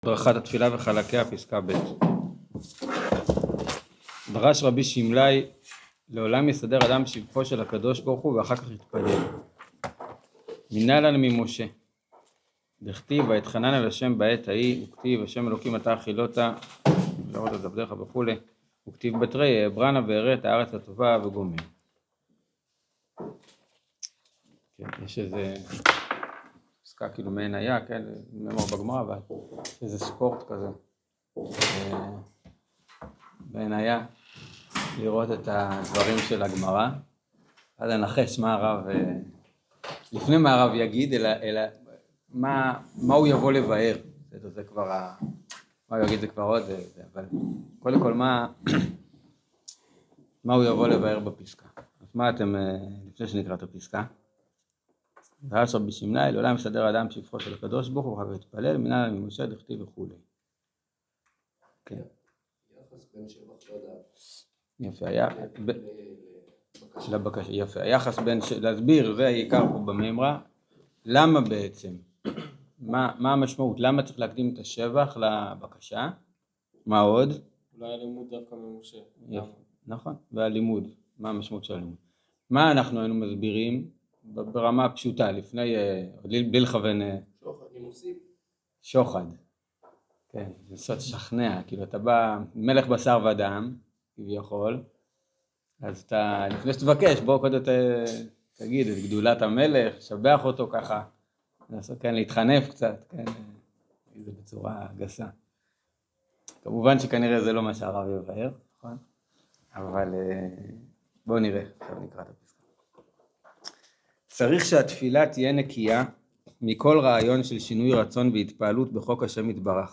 שיעור הדרכת התפילה וחלקיה פסקה ב